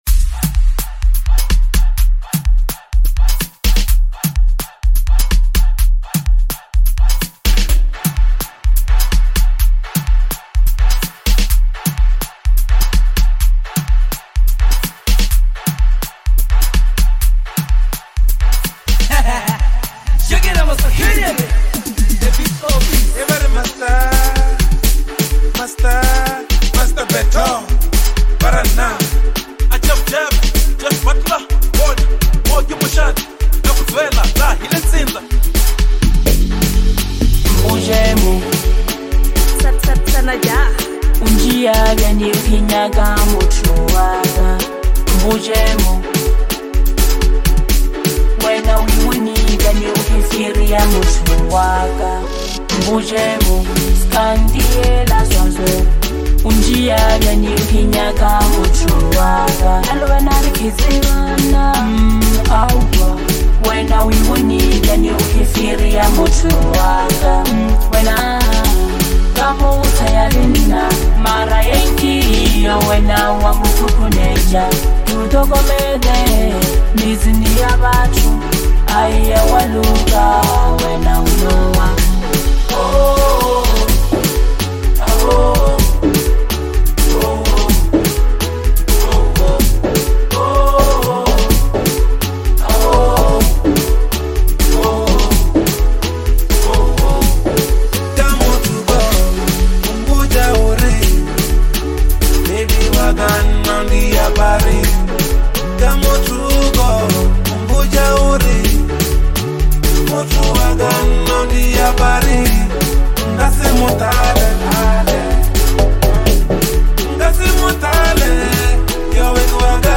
Lekompo